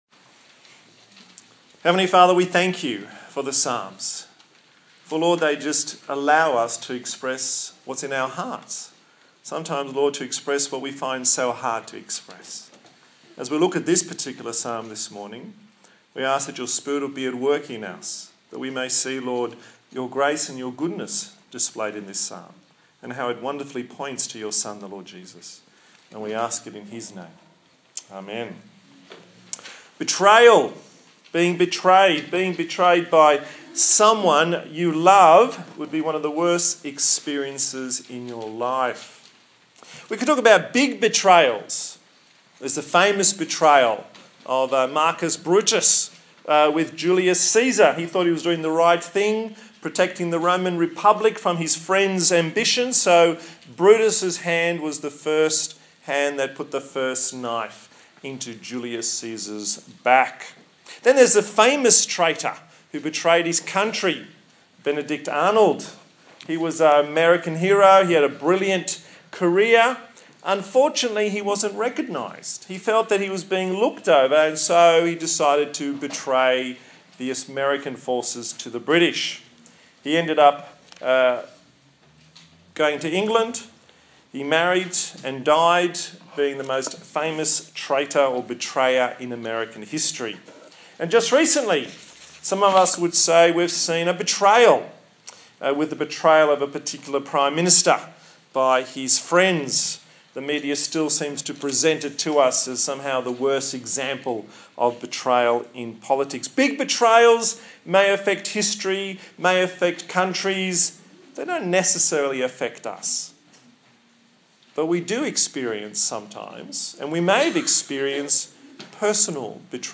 Psalm 55: a sermon
Psalm 55 Service Type: Sunday Morning Psalm 55